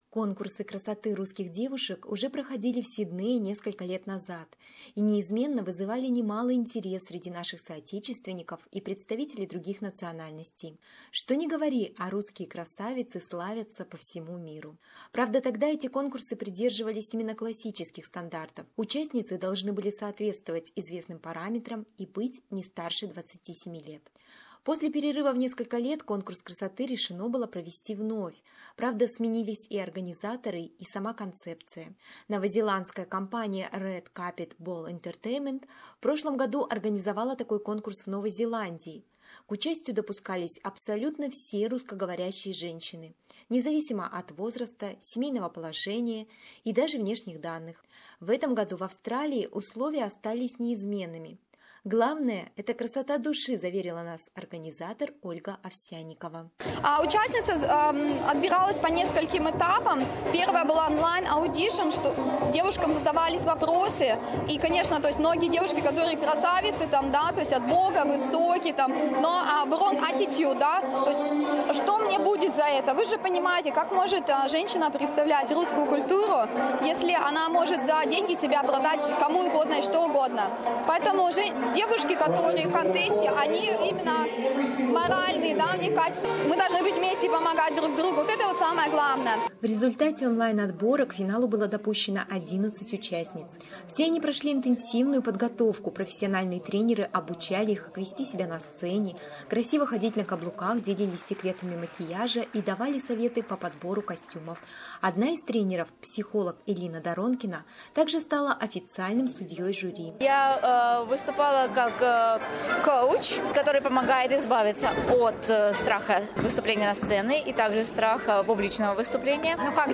Какими, и кто же получил титул первой красавицы - в нашем репортаже.